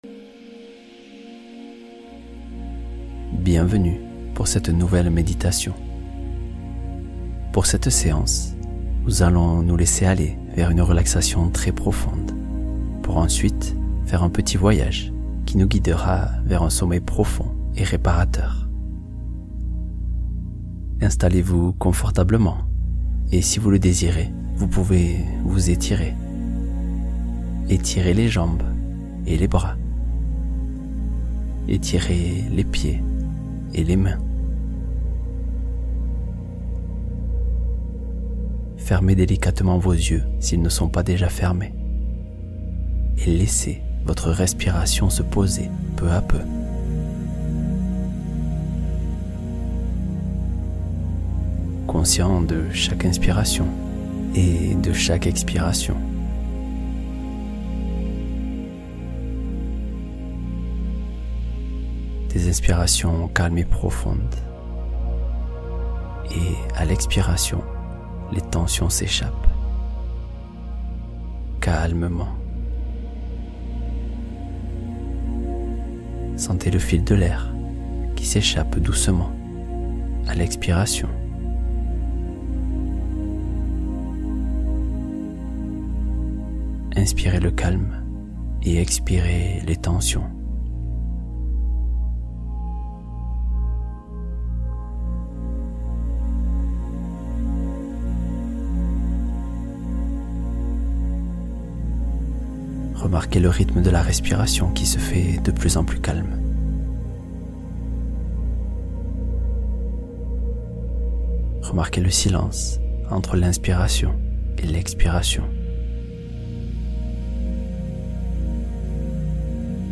Confiance activée — Méditation guidée pour initier un changement réel